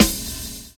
Snare (25).wav